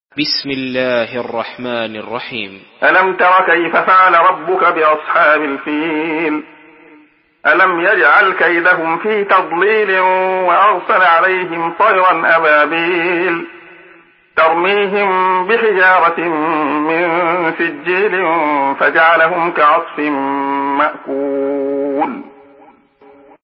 Surah Al-Fil MP3 in the Voice of Abdullah Khayyat in Hafs Narration
Surah Al-Fil MP3 by Abdullah Khayyat in Hafs An Asim narration. Listen and download the full recitation in MP3 format via direct and fast links in multiple qualities to your mobile phone.